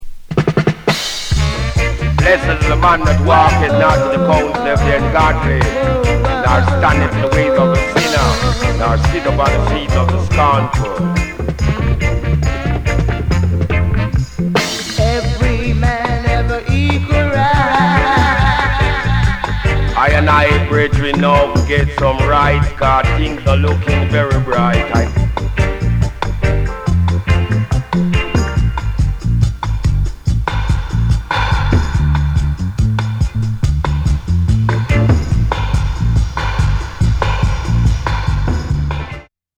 替え歌ダブ